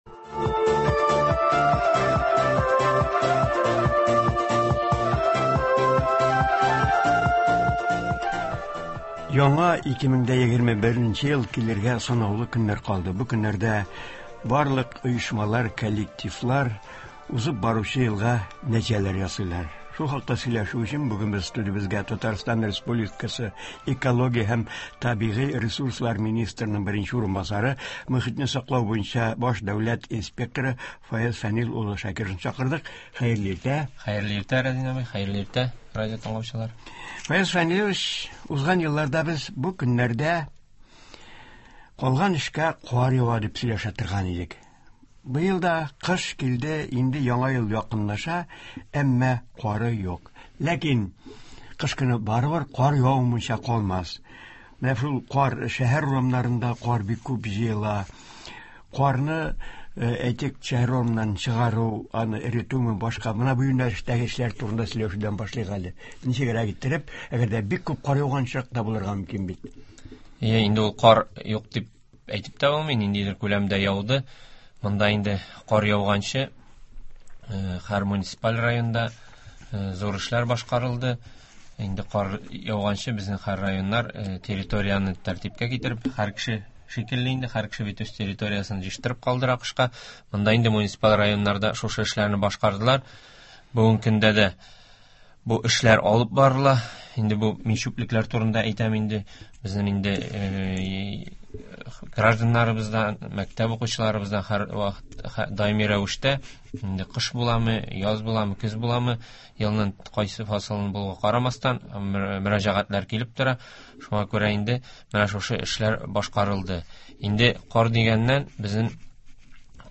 Республикабызда табигатьне саклау өлкәсендә илкүләм проектларны гамәлгә ашыруга зур игътибар бирелә, ил дәрәҗәсендә дә, Татарстан бюджетыннан да шактый күләмдә чыгымнар тотыла. Болар хакында турыдан-туры эфирда Татарстан Экология һәм табигый ресурслар министрының беренче урынбасары Фаяз Шакиров сөйләячәк һәм тыңлаучылар сорауларына җавап бирәчәк.